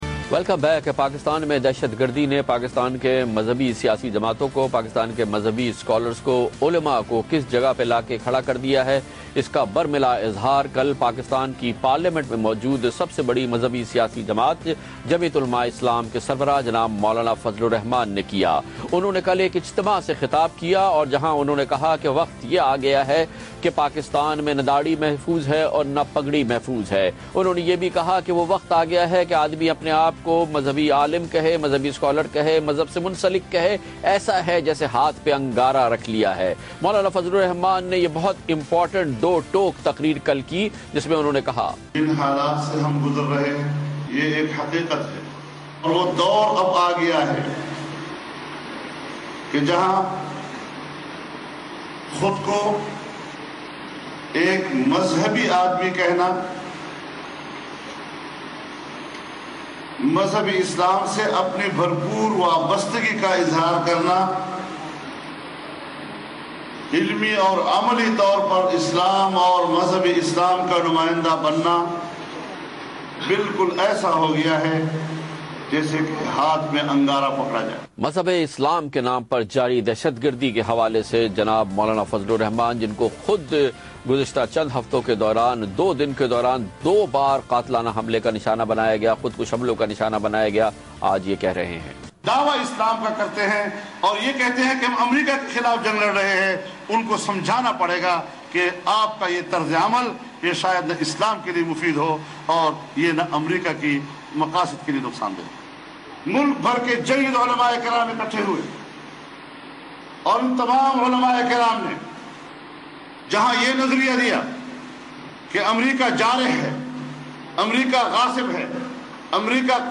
Category: TV Programs / Geo Tv / Questions_Answers /